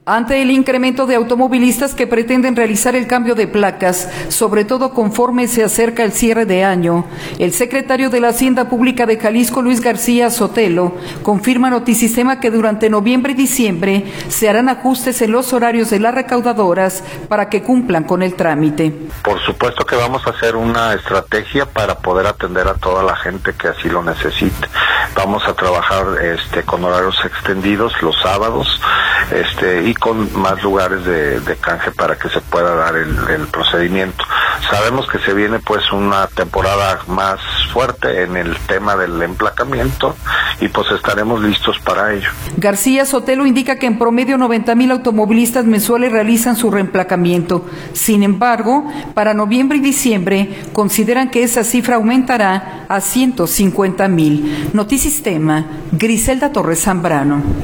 Ante el incremento de automovilistas que pretenden realizar el cambio de placas, sobre todo conforme se acerca el cierre de año, el Secretario de la Hacienda Pública de Jalisco, Luis García Sotelo, confirma a Notisistema que durante noviembre y diciembre se harán ajustes en los horario de las recaudadoras para que cumplan con el trámite.